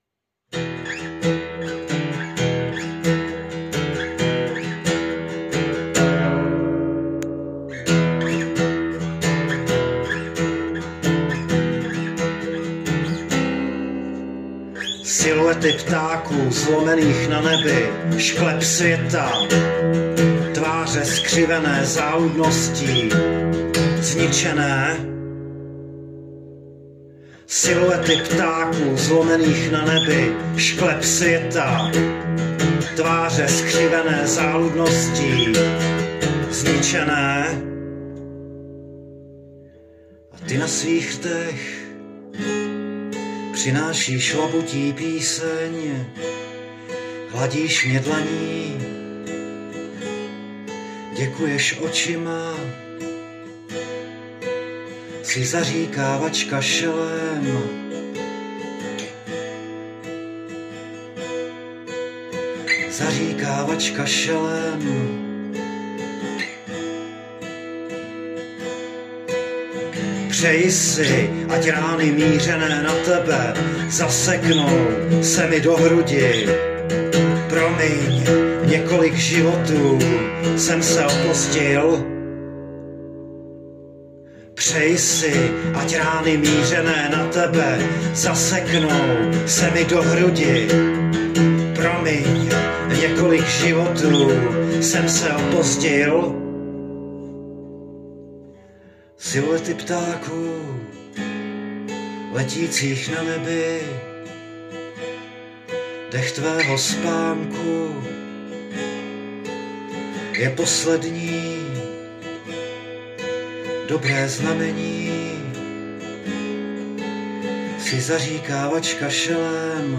/zhudebněno a nahráno bez AI
super expresívne